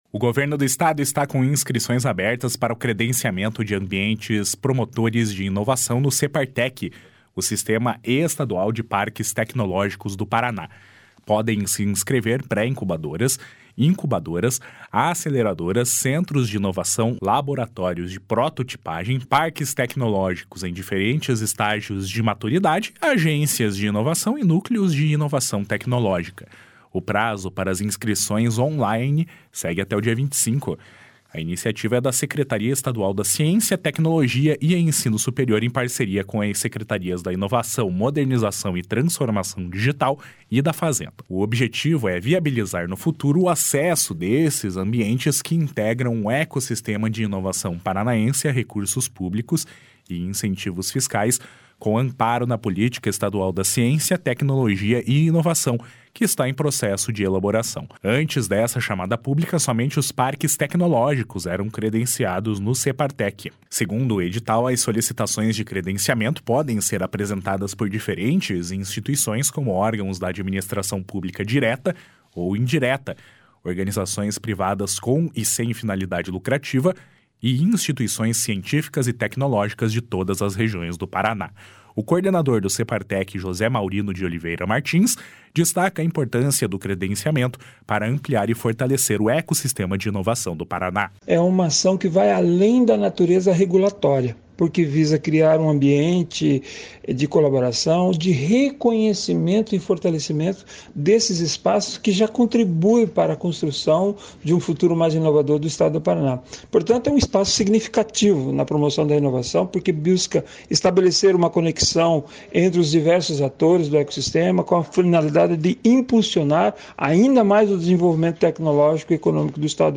Reportagem
Narração